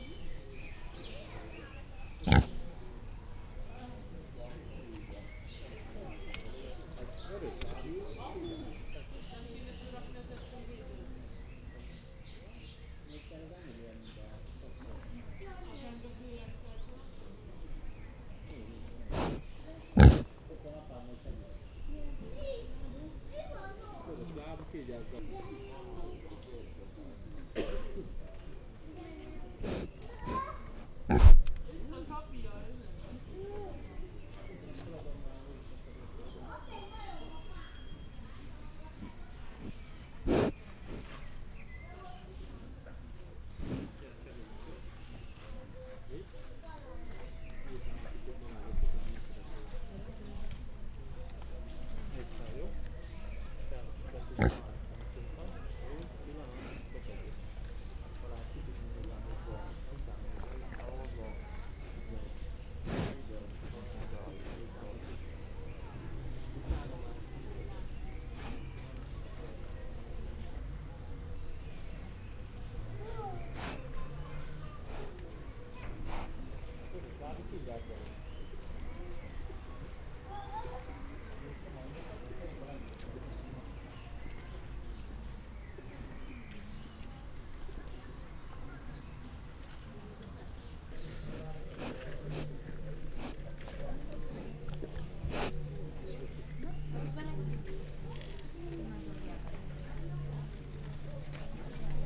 Directory Listing of /_MP3/allathangok/gyorizoo2014_professzionalis/vietnamicsungohasusertes/
rofogesesszuszogas_sds01.41.WAV